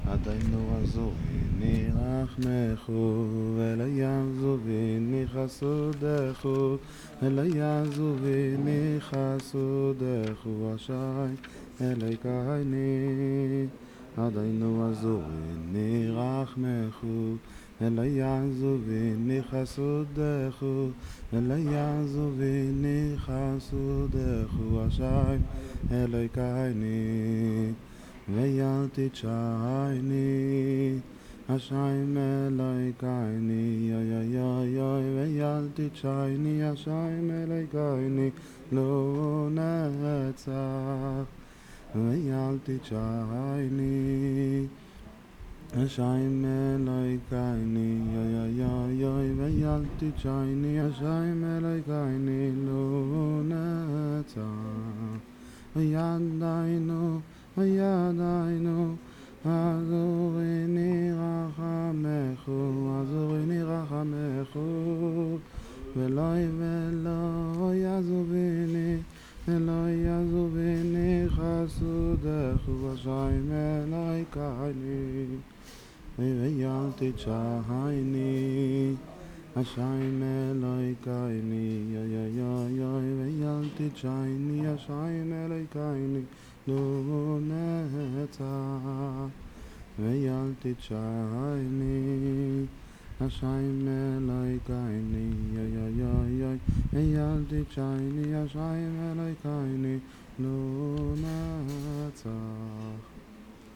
I returned the next day for shacharit and found one chasid who didn't mind recording the song for me.
The words of the song come from Nishmat in the morning service for shabbat and holidays. You may need to read them first to understand them, because the Tzanzer accent is so strong. For example, the "oo" sound is pronounced "ee", so "Eloheinu" (our God) becomes "Elawkeini".